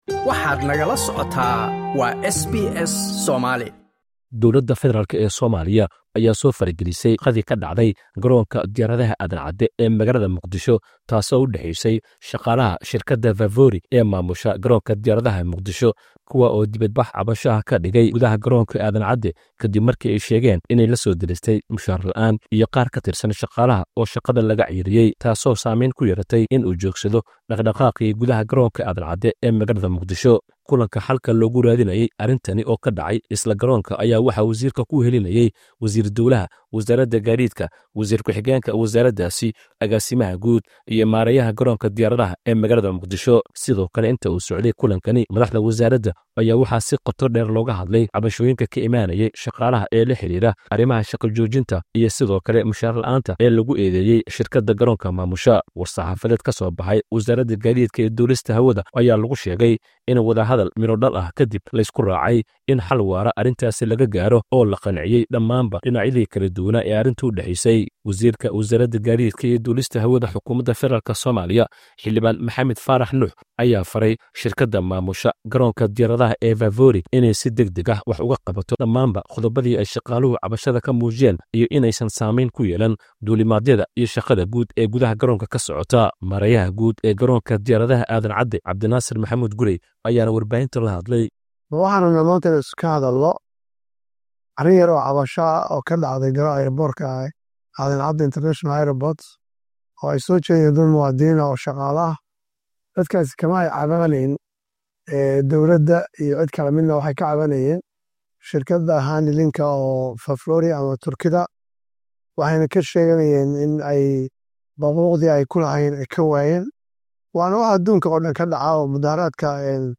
Somalia News - Warka Soomaaliya: Arbaco 12 November 2025